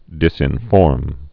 (dĭsĭn-fôrm)